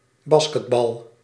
Ääntäminen
US : IPA : [ˈbæs.kɪt.ˌbɔl]